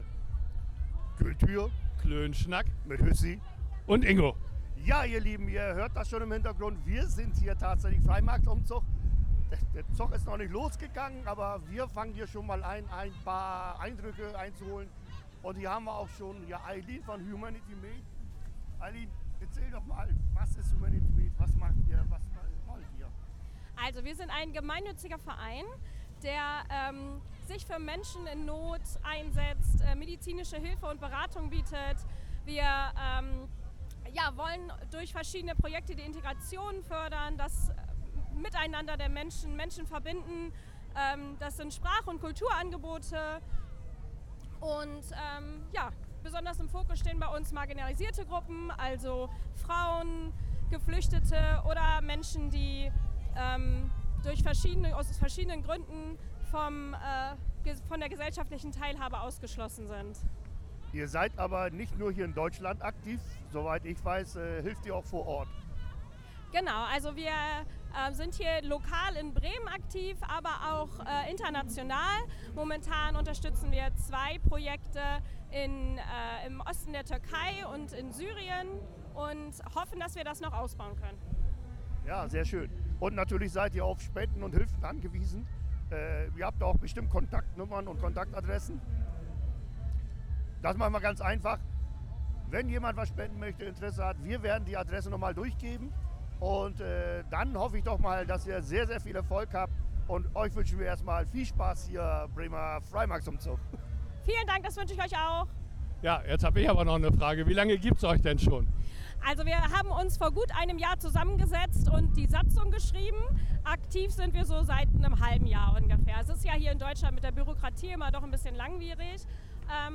Wir waren auf dem Freimarktsumzug und haben uns mit ein paar Leuten unterhalten. Die Tonqualität mögt ihr uns verzeihen, wir geloben Besserung, aber bei dem Wetter... :-)